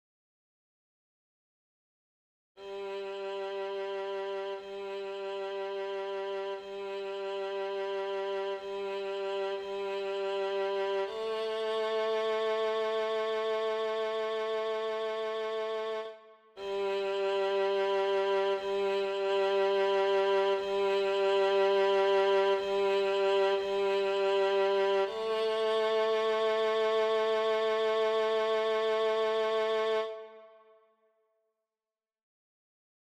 Key written in: C Major